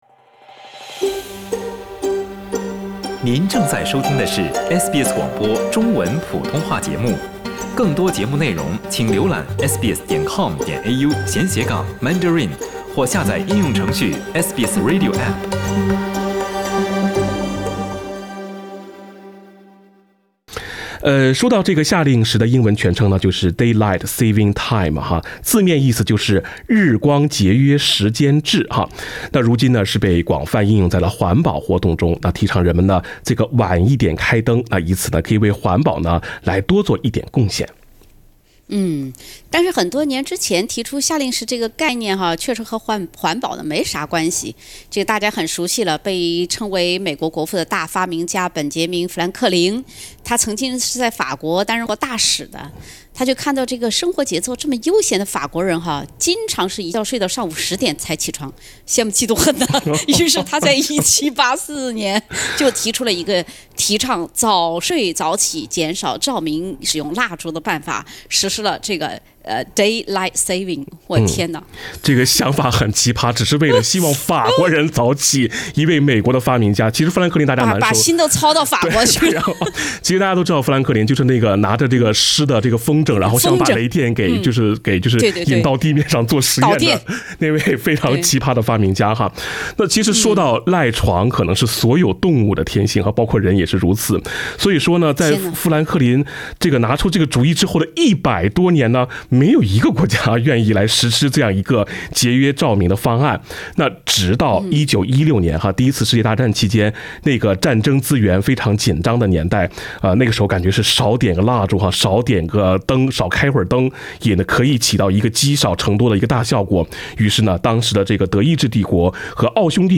用来提倡节能环保的夏令时还会增加患心脏病和中风的风险？我们真的有必要一年做这两次时间穿越吗？（点击封面图片，听主持人闲聊夏令时）